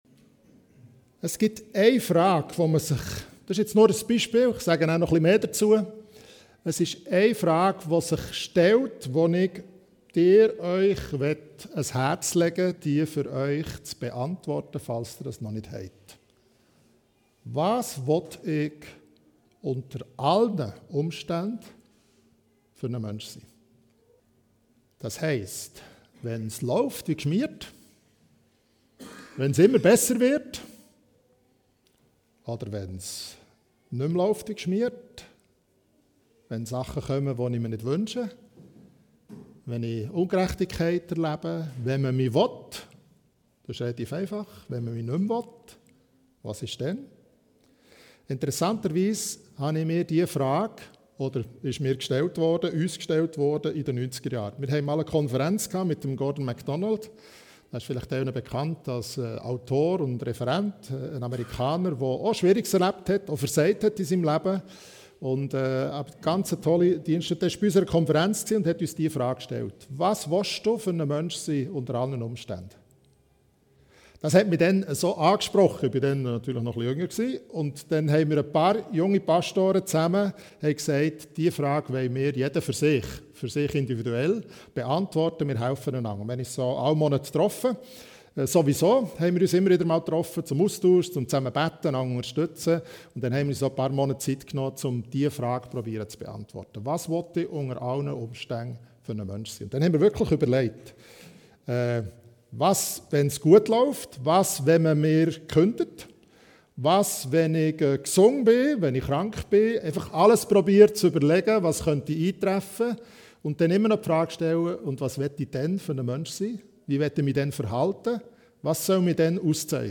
Bible Text: Epheser 3. 16 – 17 | Prediger/in